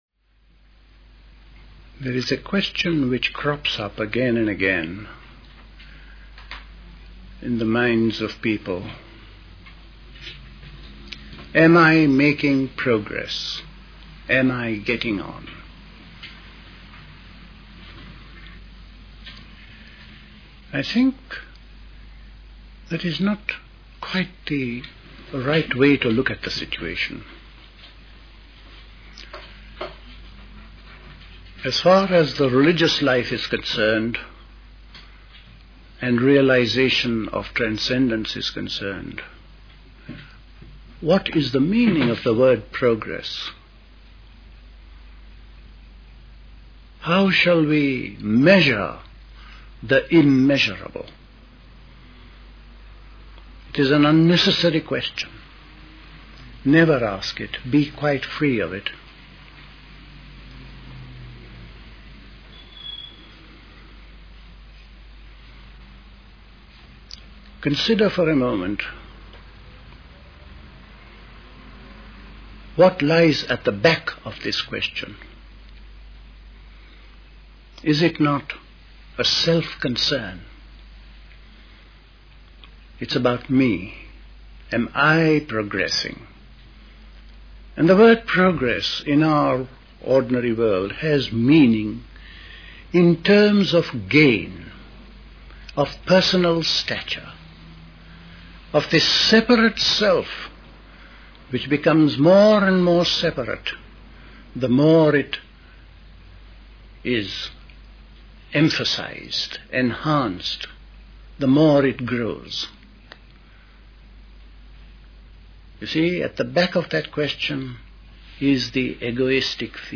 A talk given
at Dilkusha, Forest Hill, London